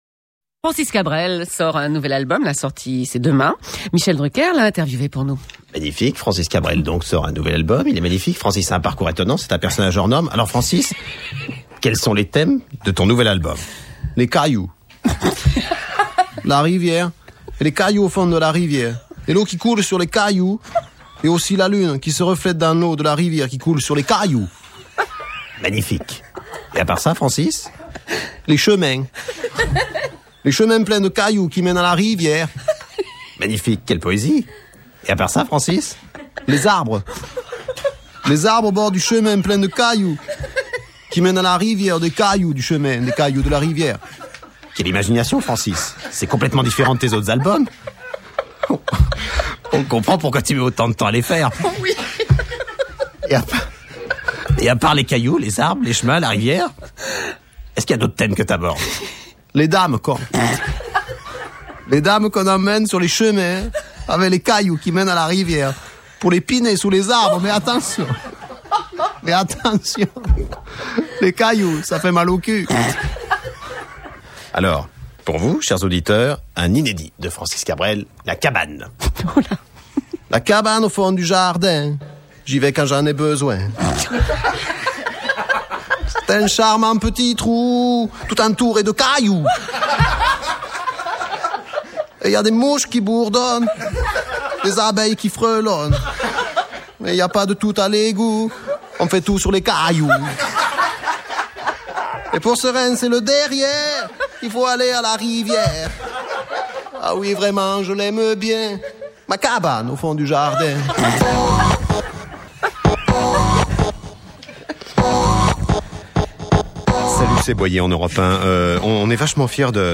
- Une interview de